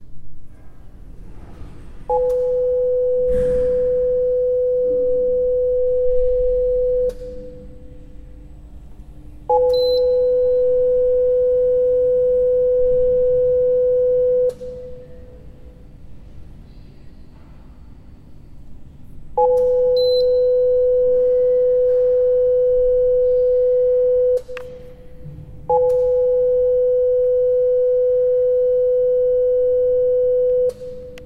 The keypad at the entrance of our new building makes
the most wonderful sound, exactly like the intro to structures by John Baker and I think it may be synthesized the same way.
keypad.mp3